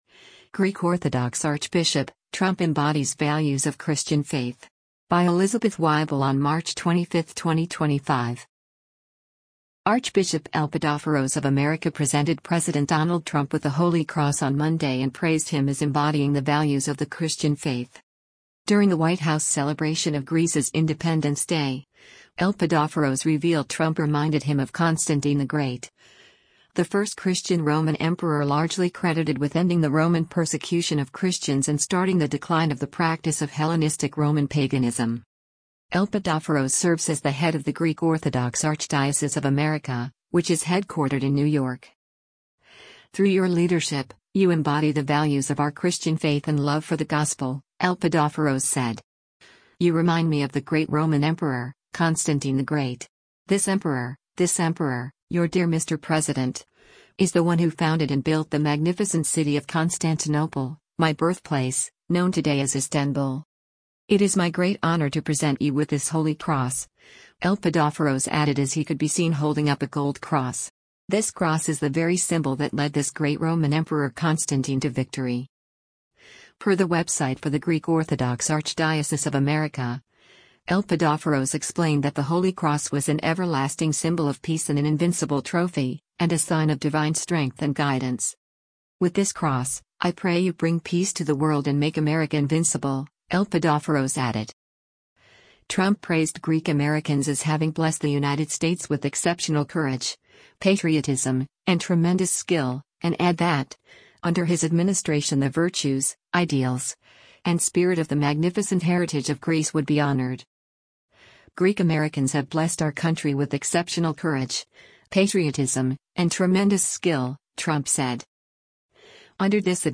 During a White House celebration of Greece’s Independence Day, Elpidophoros revealed Trump reminded him of Constantine the Great, the first Christian Roman emperor largely credited with ending the Roman persecution of Christians and starting the decline of the practice of Hellenistic Roman paganism.